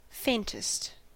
Ääntäminen
Ääntäminen US Haettu sana löytyi näillä lähdekielillä: englanti Käännöksiä ei löytynyt valitulle kohdekielelle. Faintest on sanan faint superlatiivi.